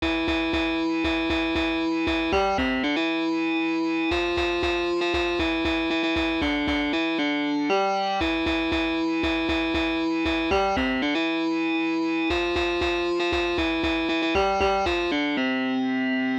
So I looked at how people use computers to create music, preferably using open source tools and came across a few articles and the idea of a tracker – something from the 90s. This is a tool where you lay out music and play it.